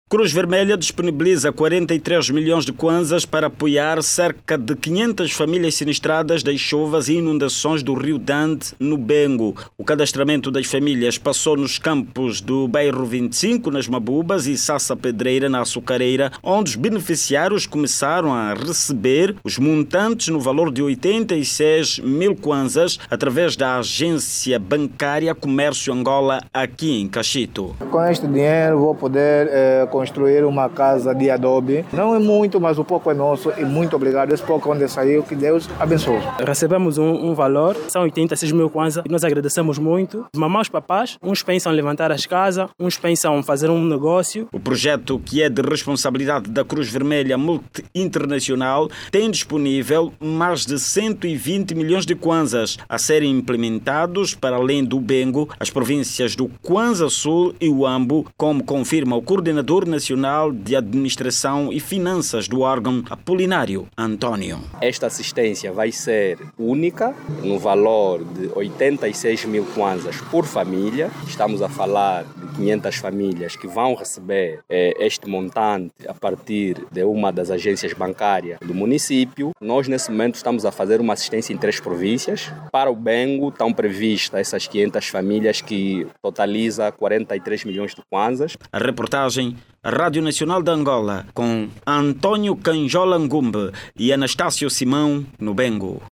A cruz vermelha de Angola, vai apoiar mais de quinhentas famílias reassentadas nos bairros vinte e cinco nas Mabubas e Sassa Pedreira, na Açucareira, na província do Bengo. Os beneficiários vão receber um montante no valor de oitenta e seis mil kwanzas cada, para retomarem a vida depois das inundações dos seus bairros, provocadas pelas cheias do rio Dande. Clique no áudio abaixo e ouça a reportagem